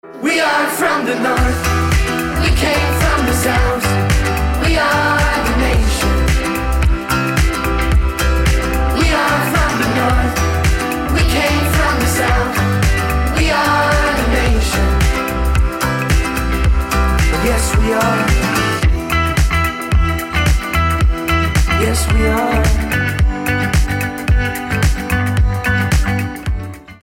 • Качество: 320, Stereo
гитара
позитивные
мужской вокал
громкие
клавишные
Dance Pop
электрогитара
хор
воодушевляющие